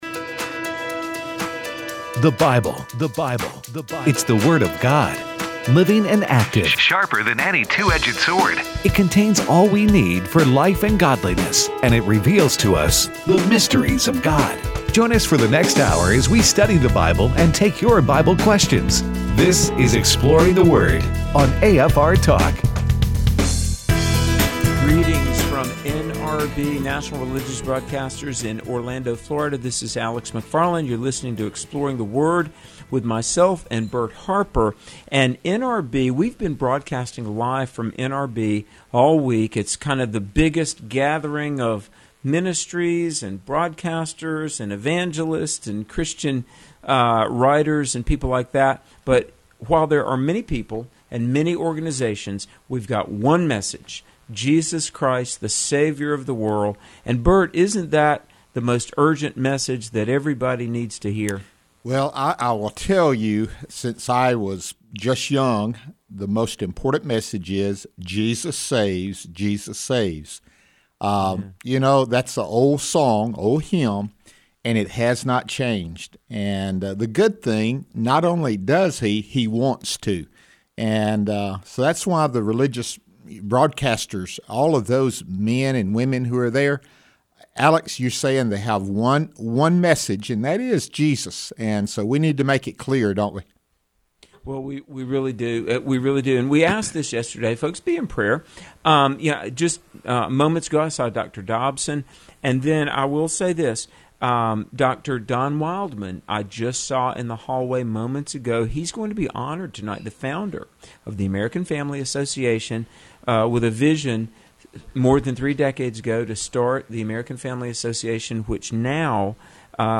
Live From NRB Day 4